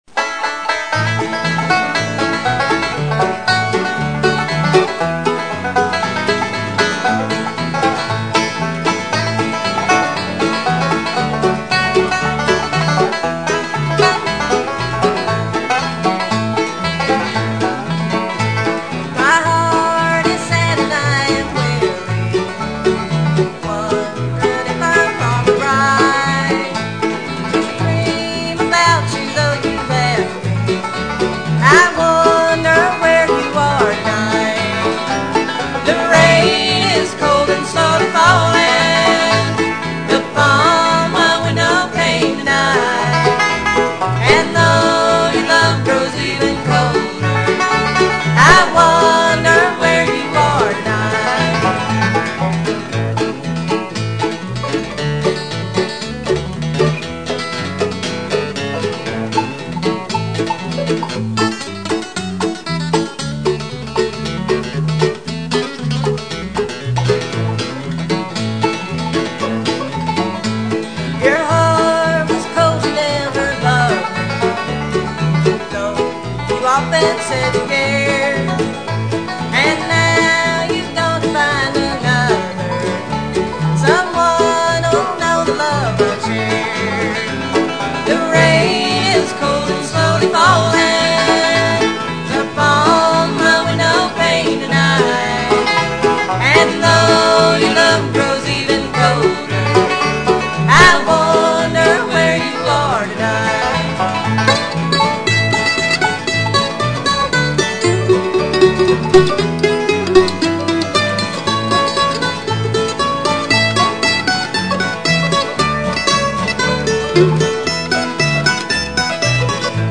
mandolin, vocals
bass, vocals
guitar, banjo, vocals
guitar only